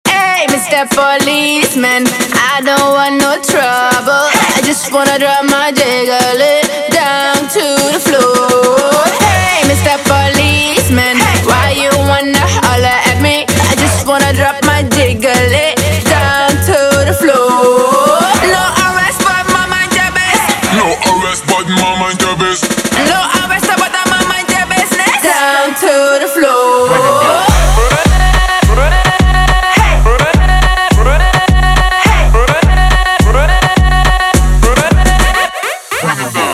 из Клубные
Категория - клубные.